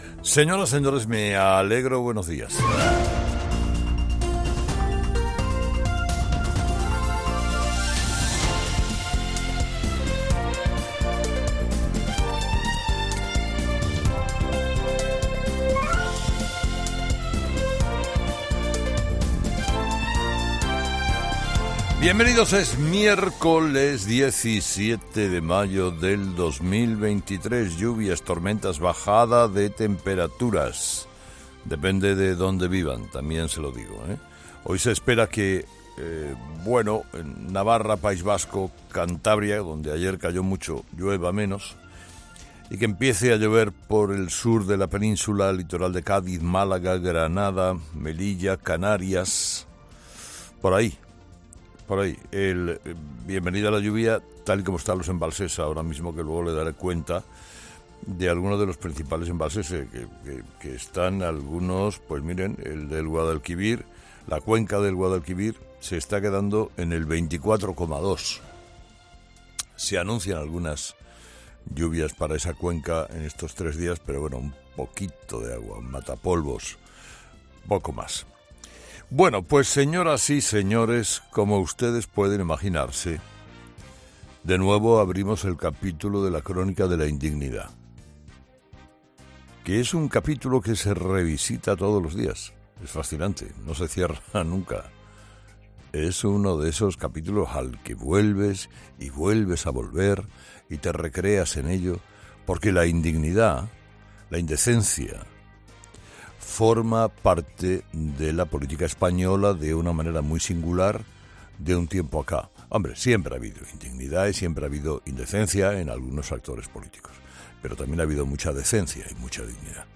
Escucha el análisis de Carlos Herrera a las 06:00 horas en Herrera en COPE este miércoles 17 de mayo de 2023
Carlos Herrera, director y presentador de 'Herrera en COPE', comienza el programa de este martes analizando las principales claves de la jornada, que pasan, entre otros asuntos, por el anuncio de Bildu sobre la renuncia de los candidatos a las elecciones municipales.